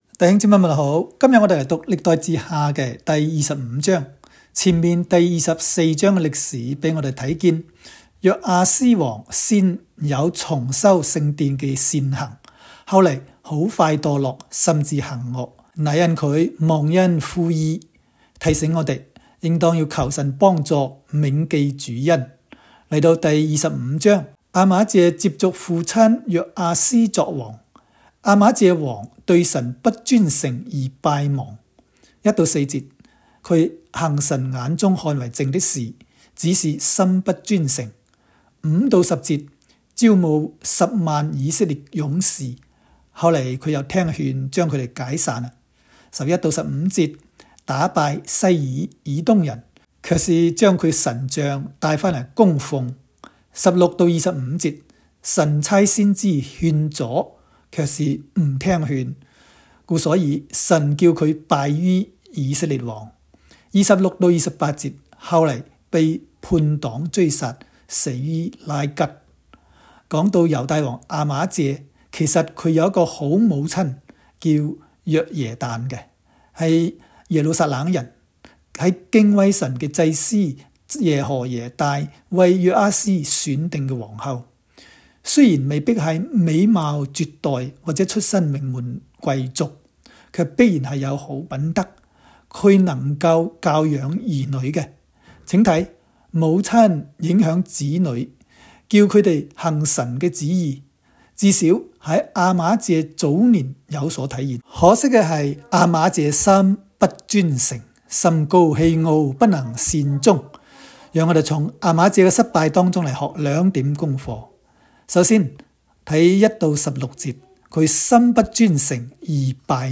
代下25（讲解-粤）.m4a